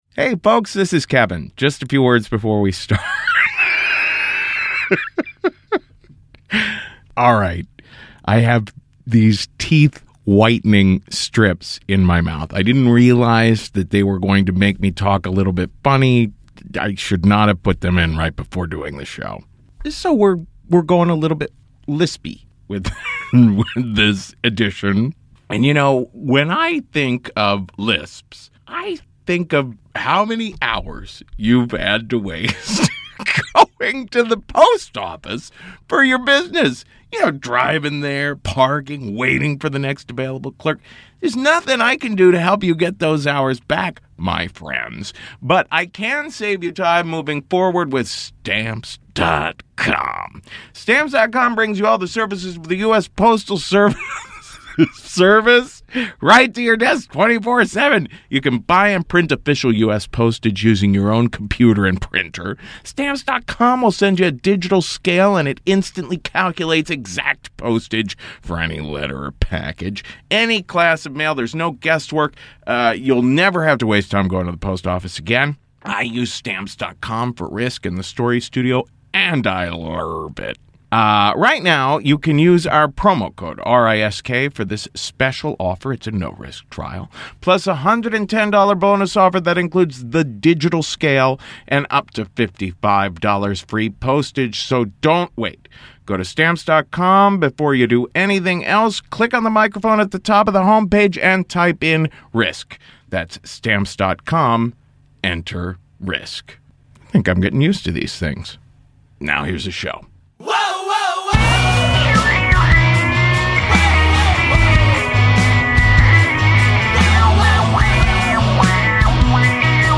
tell spooky yarns in our 2013 Halloween episode.